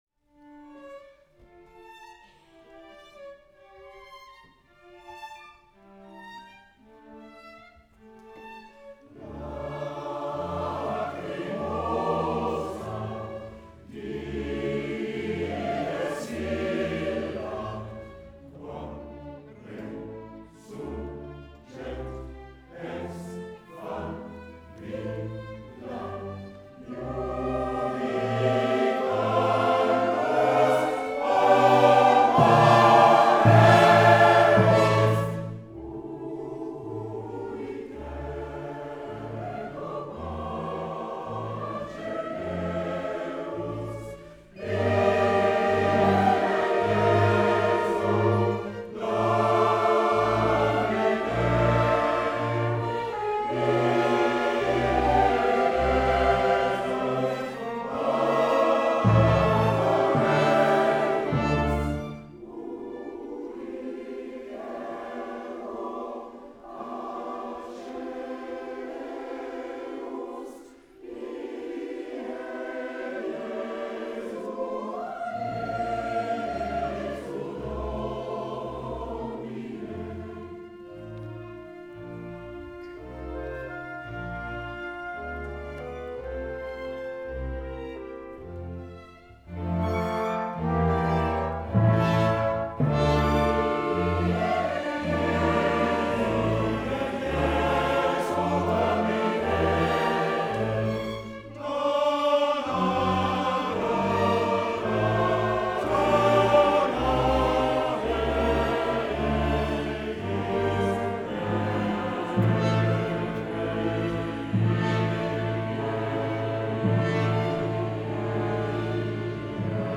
Captation concert acoustique
Prise de son avec microphones DPA et enregistreur NAGRA.
( Wav stéréo 48Khz 24Bits )
Lacrimosa du Requiem de MOZART KV626 par les chorales Konzertchor Landshut et le chœur Prélude de Compiègne
au Théâtre Impérial de Compiègne